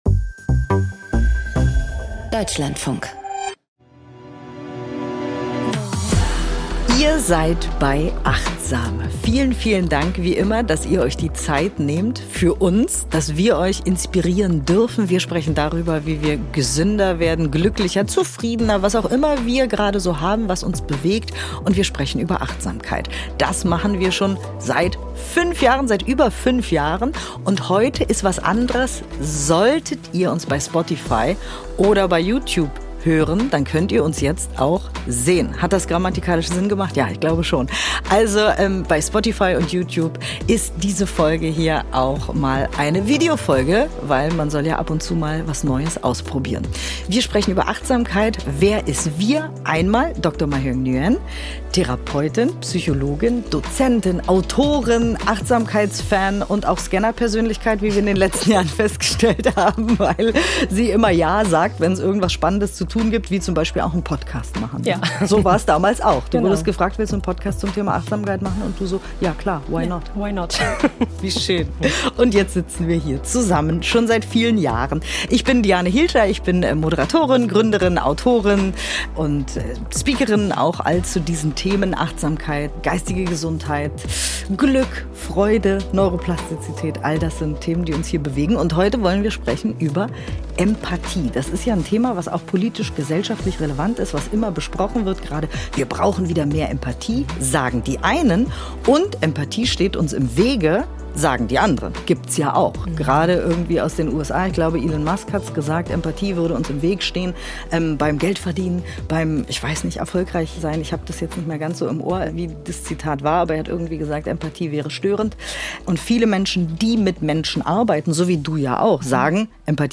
********** An dieser Stelle findet ihr die Übung: 00:33:46 - Geleitete Meditation zum Thema Empathie ********** Quellen aus der Folge: Fredrickson, B. L., Cohn, M. A., Coffey, K. A., Pek, J., & Finkel, S. M. (2008).